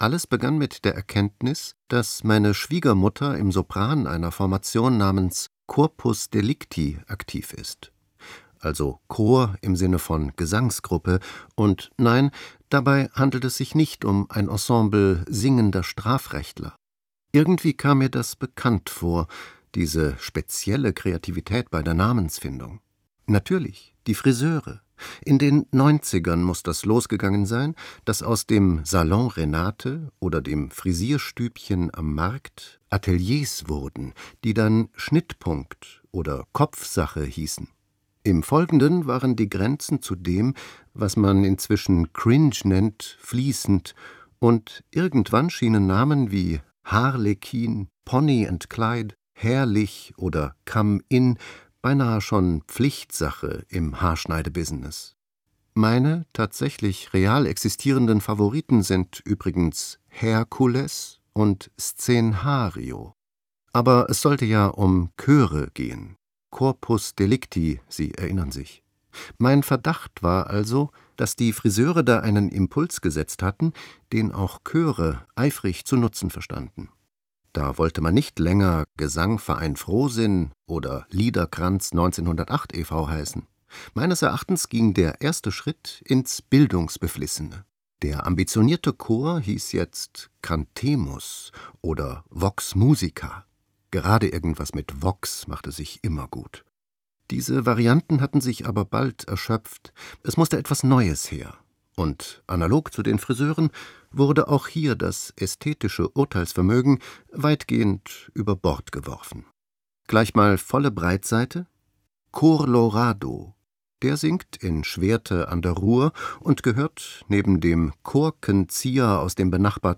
Glosse